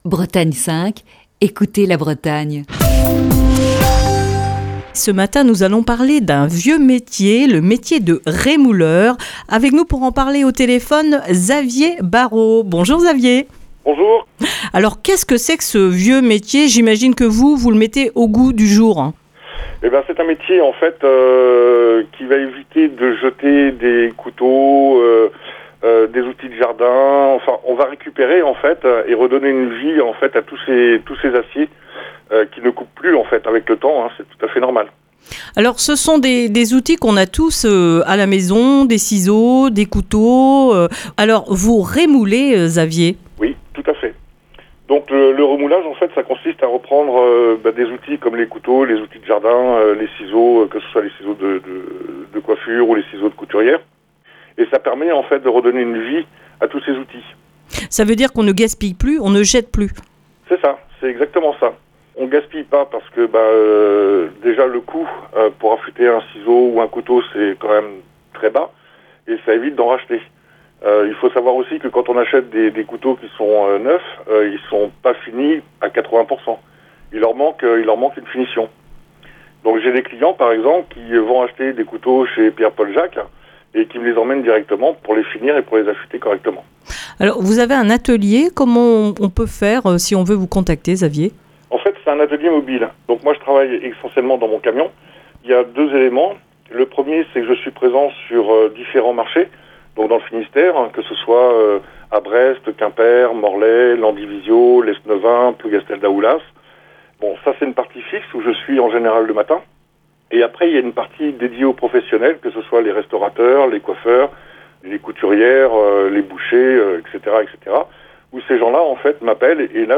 Dans le coup de fil du matin de ce mardi